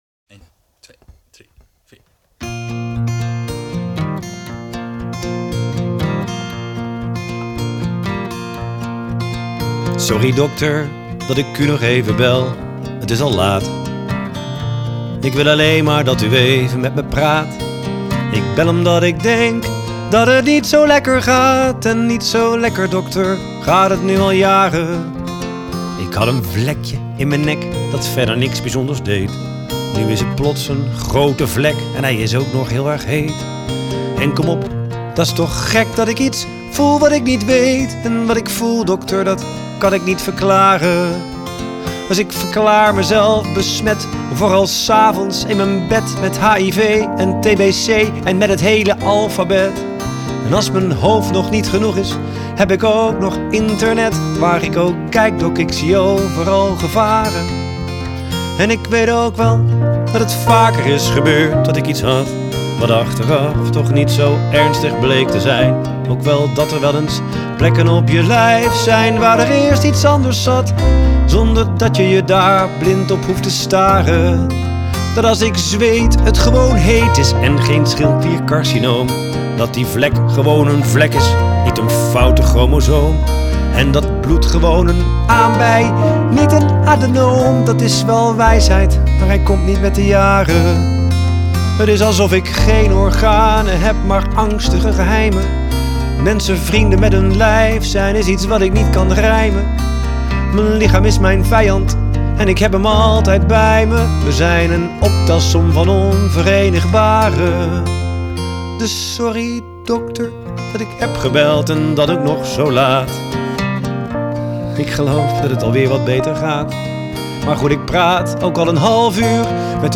Zang, gitaar
Gitaar
Bas, toetsen
Slagwerk